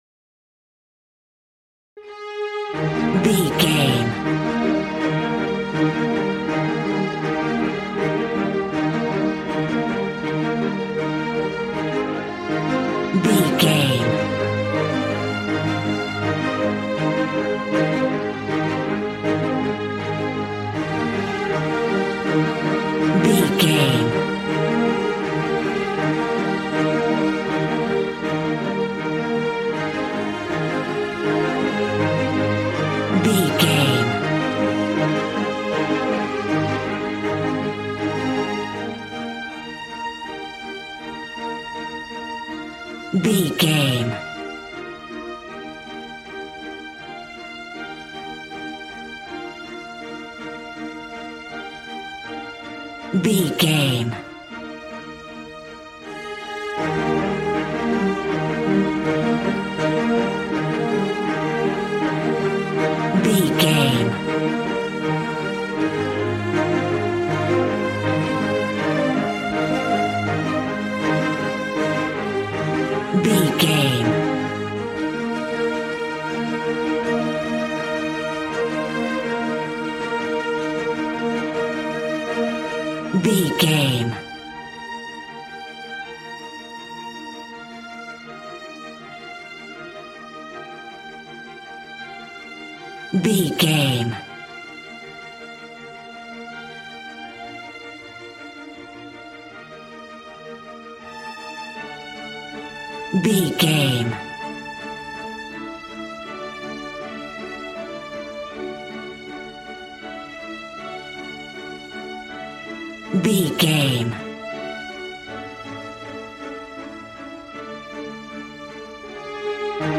Ionian/Major
E♭
regal
strings
brass